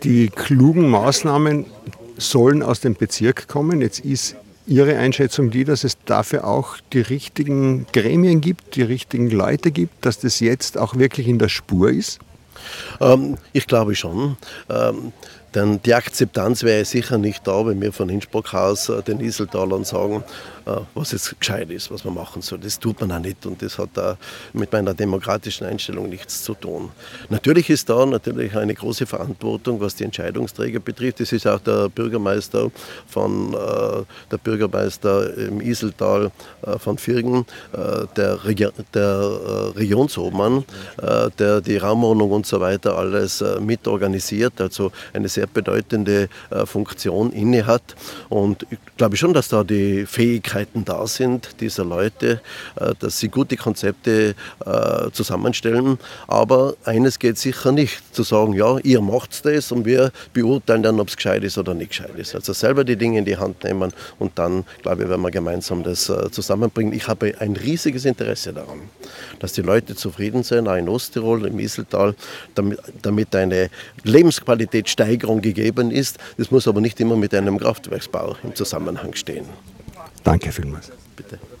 Natura 2000 sei der bessere Weg im Iseltal, meint der Landeshauptmann. Ein Audio-Interview.